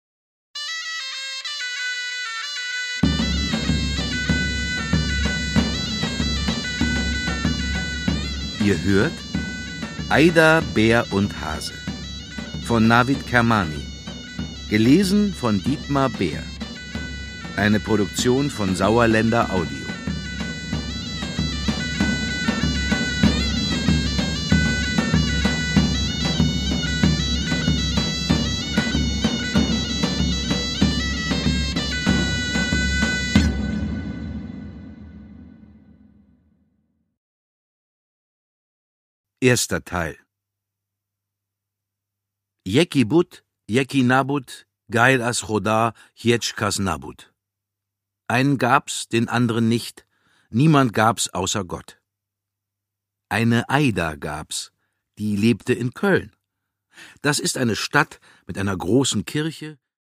Dr. Navid Kermani: Ayda, Bär und Hase (Ungekürzte Lesung mit Musik)
Gelesen von: Dietmar Bär